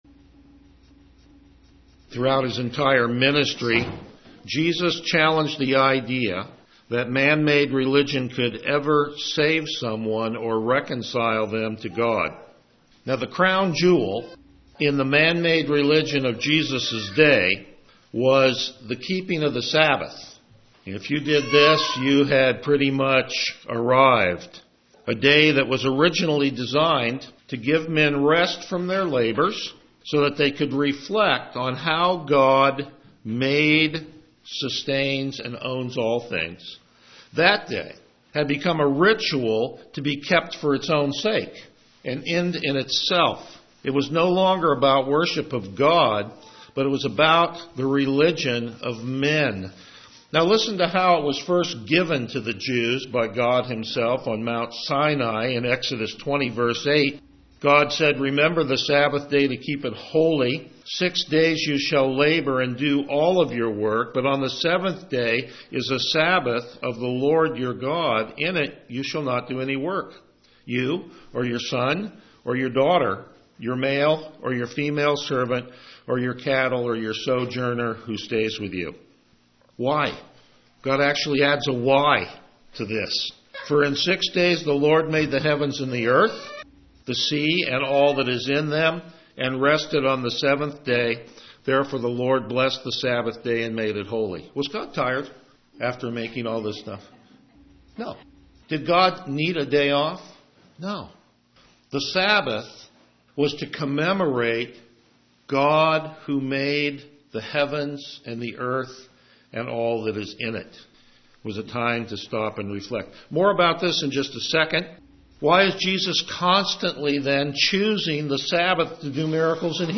January 27, 2013 Honoring God the Father Series: The Gospel of John Passage: John 5:16-27 Service Type: Morning Worship Download Files Notes Topics: Verse By Verse Exposition « Faithless Healing How Is Christ’s Murder Glorious?